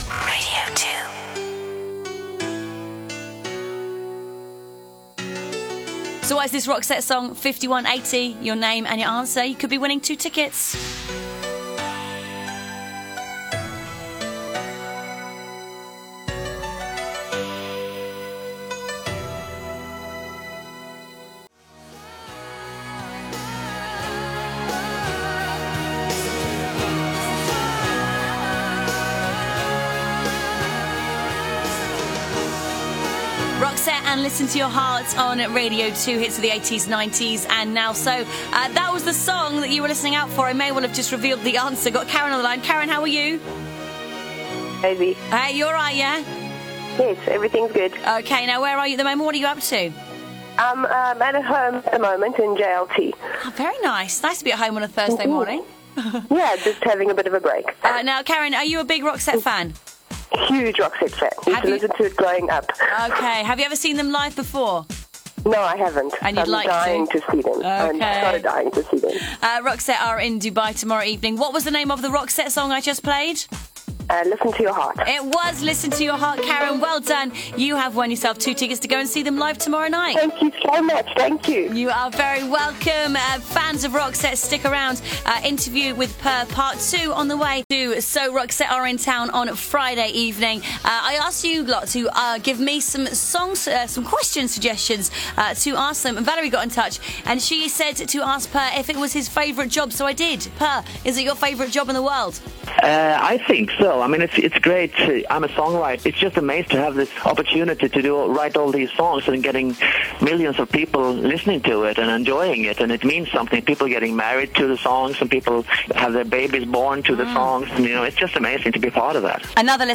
The first part is missing, as you can hear.)